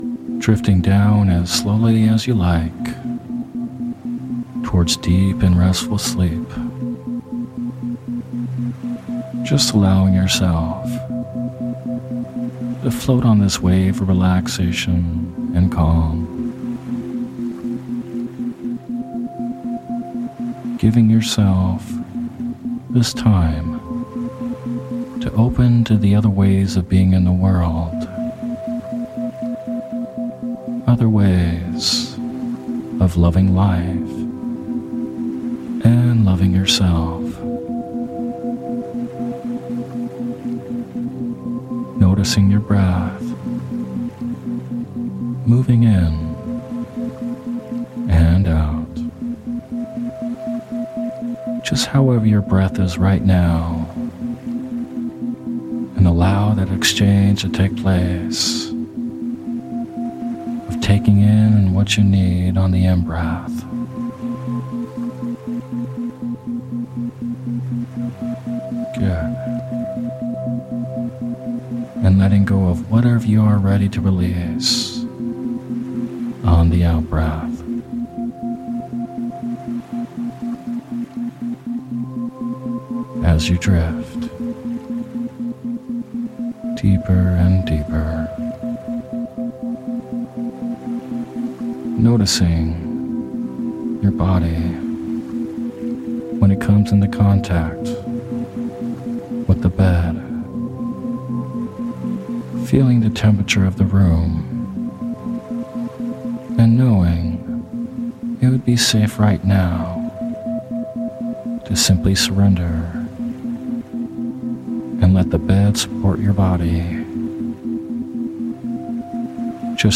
Sleep Hypnosis For Self Love With Isochronic Tones
In this sleep hypnosis audio, we’ll go through various visualizations to help develop self-love for yourself. This meditation includes dreamy meditation music.